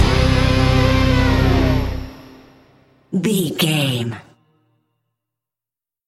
Thriller
Aeolian/Minor
WHAT’S THE TEMPO OF THE CLIP?
synthesiser